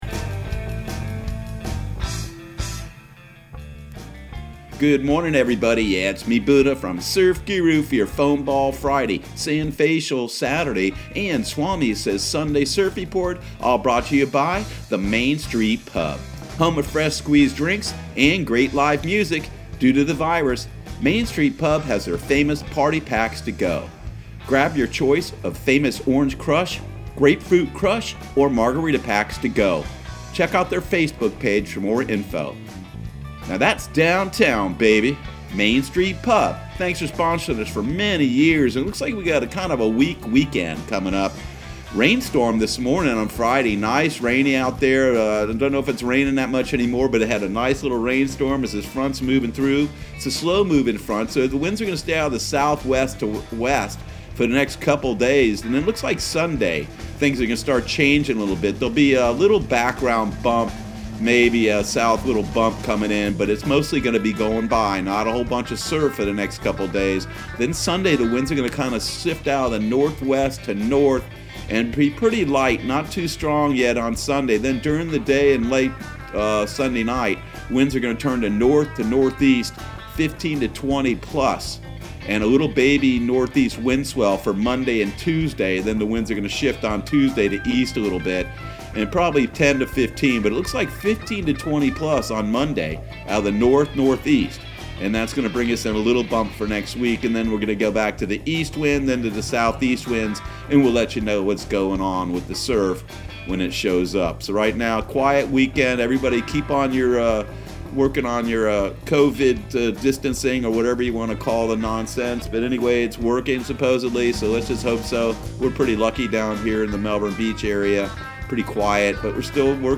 Surf Guru Surf Report and Forecast 04/24/2020 Audio surf report and surf forecast on April 24 for Central Florida and the Southeast.